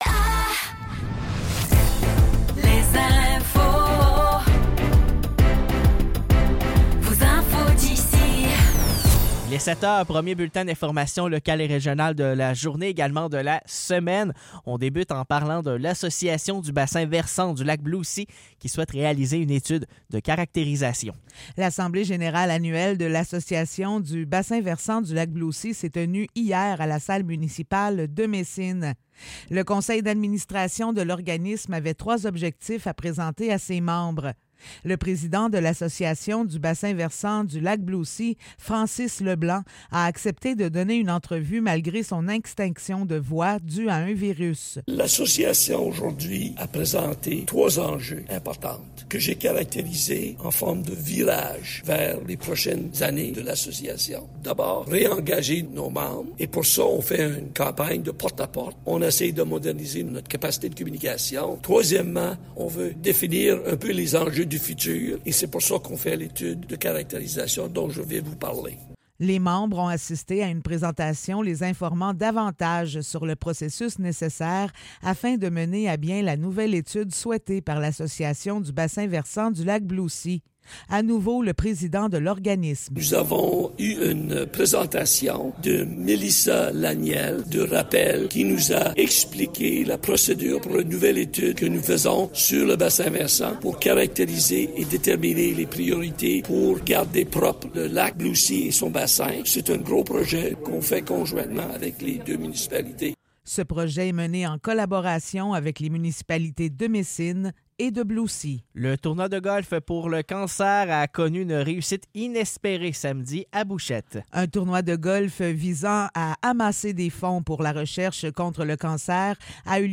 Nouvelles locales - 12 août 2024 - 7 h